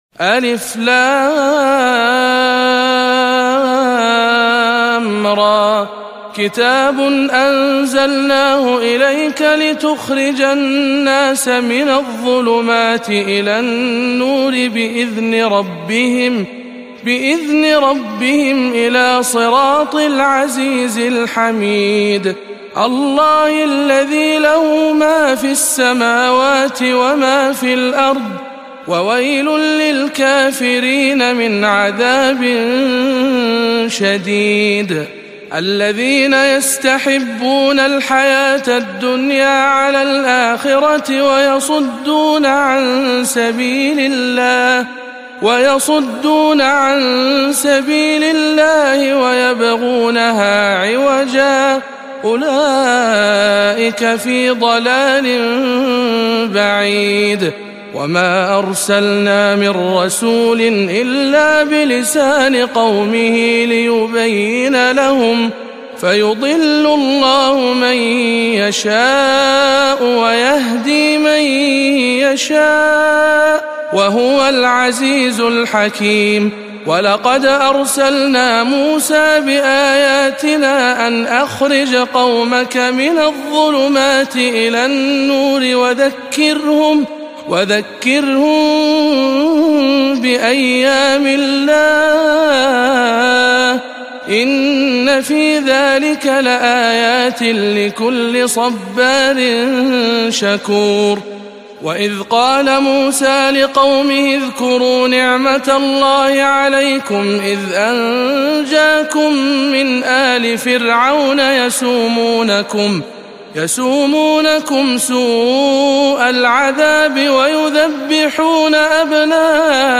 08. سورة إبراهيم بجامع الأمير محمد بن سعود ببلحرشي - رمضان 1438 هـ